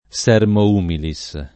sermo [lat. S$rmo] s. m.; pl. sermones [Serm0neS] — presente nell’uso it. come componente di locuz. stor.: sermo urbanus [S$rmo urb#nuS], sermo rusticus [S$rmo r2StikuS], sermo humilis [